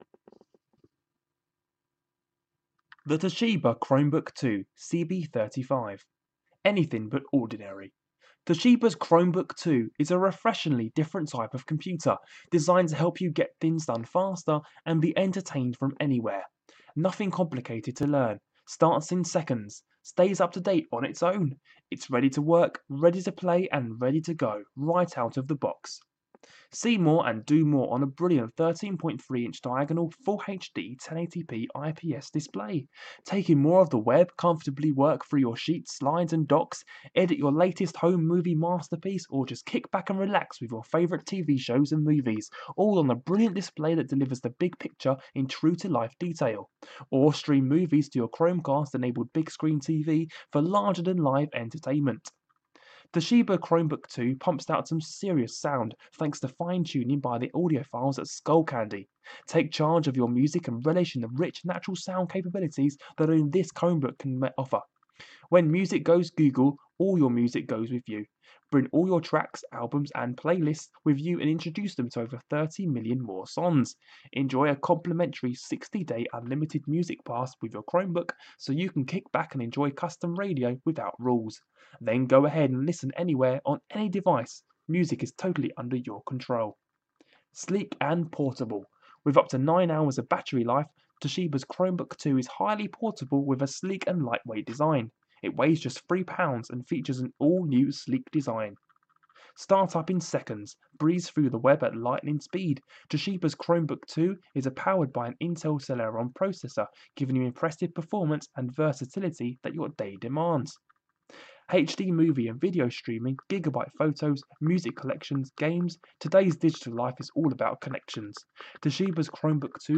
The aim of this Audioboom channel is to provide audio descriptions for popular products in a range of categories.